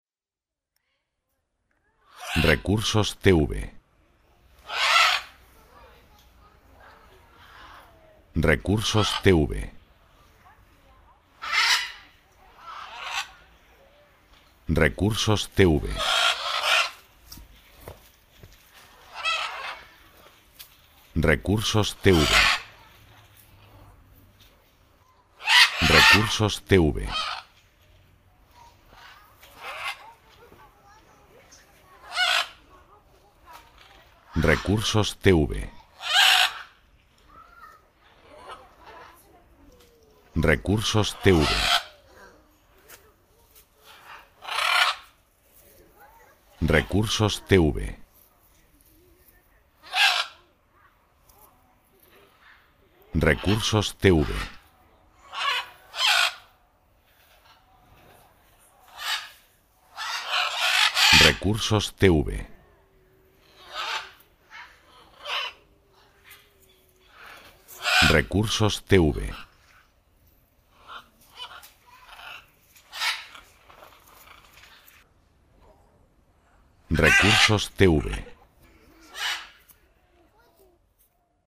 Efecto de sonido de loros.
loros.mp3